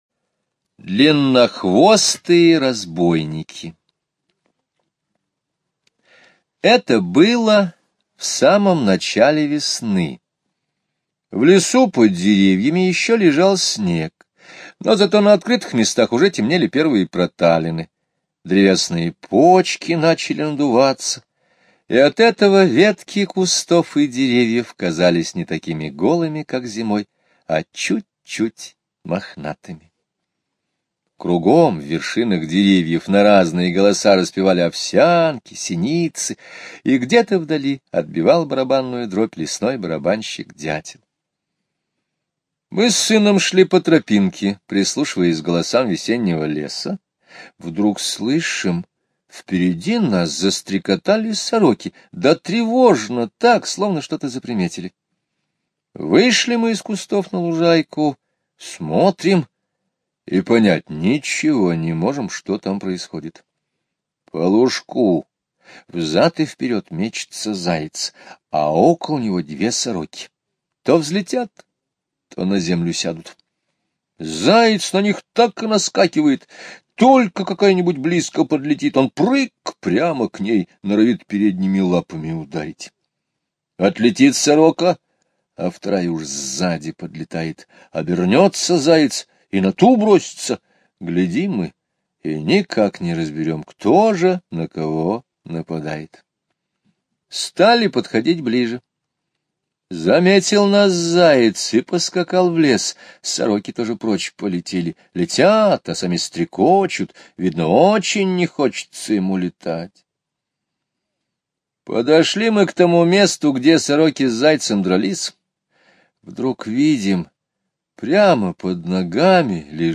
Слушайте аудио рассказ "Длиннохвостые разбойники" Скребицкого Г. онлайн на сайте Мишкины книжки. Однажды рассказчик с сыном весной гуляли по лесу и увидели, как зайчиха отбивается от двух сорок, которые хотят схватить зайчонка. skip_previous play_arrow pause skip_next ...